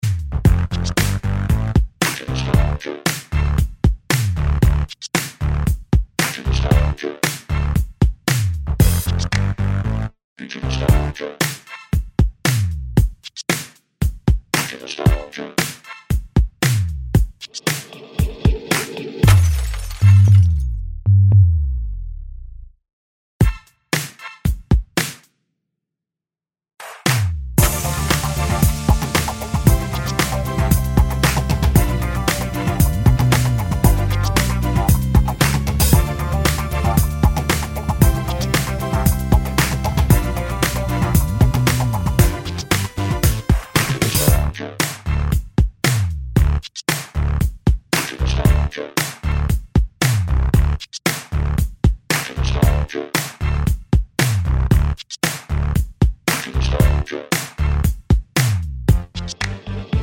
no Backing Vocals with Vocoder Pop (2010s) 3:03 Buy £1.50